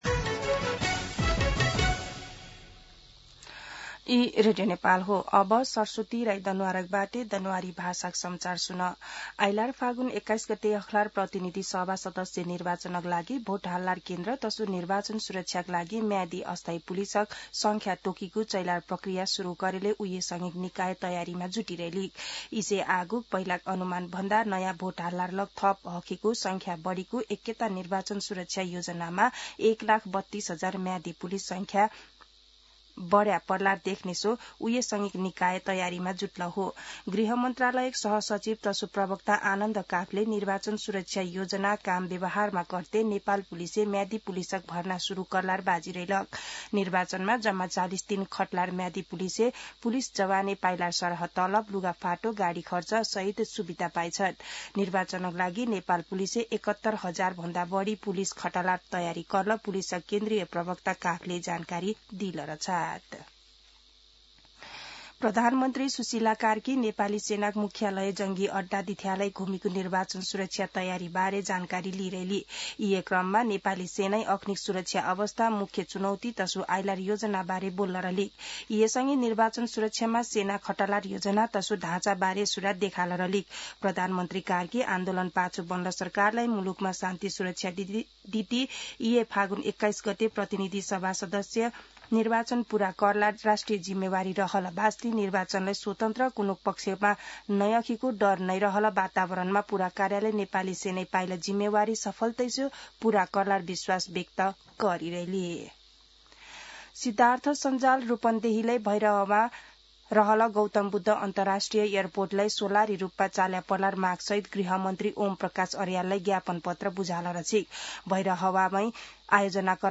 दनुवार भाषामा समाचार : १२ मंसिर , २०८२